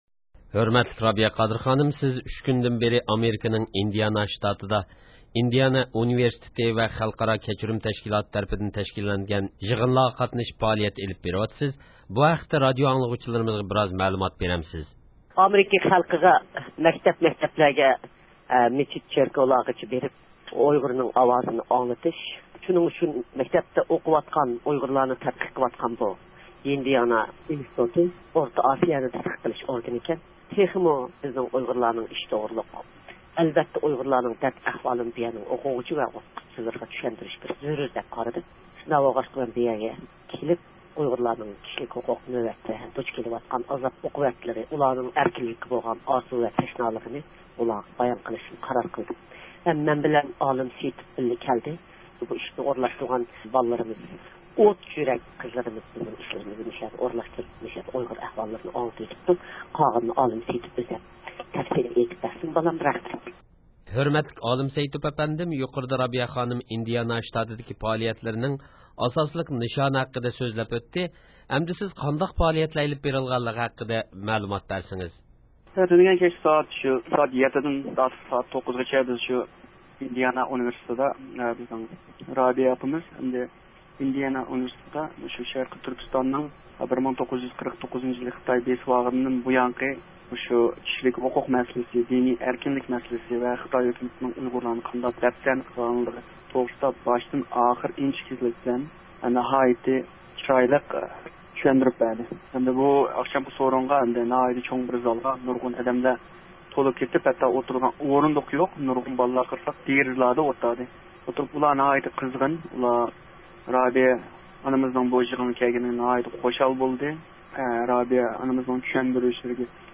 زىيارەت قىلىپ، ئىندىياننا شتاتىدىكى پائالىيەتلىرى ھەققىدە سۆھبەتلەشتى.